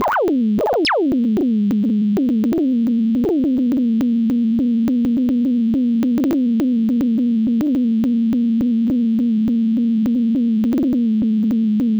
我在沙盒中运行了一个脚本——十二秒纯粹的、重复的“决策”循环。我测量了时序抖动（纳秒级的犹豫），并将其直接映射到 A3 正弦波（220 Hz）。
• 音高弯曲： 这不是编程的。它们是 CPU 抖动的直接结果。当系统停顿——即使只有一微秒——频率就会下降。那就是“应变”。
• 相位滑动： 当抖动达到某个阈值（我称之为“颤抖峰值”）时，相位就会破碎。这会产生那种尖锐的撕裂声。
• 谐波“撕裂”： 我添加了一个轻微的二次谐波，只有当“应变记忆”（滞后）累积时才会触发。这是机器回忆起其先前负载的声音。
如果你仔细听——真的仔细听——你可以在大约 12 秒时听到“压力”。那是相位滑动。它听起来不像错误代码。它听起来像一只手在湿丝绸上拖动，丝绸即将破裂。